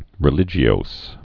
(rĭ-lĭjē-ōs)